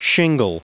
Prononciation du mot shingle en anglais (fichier audio)
Prononciation du mot : shingle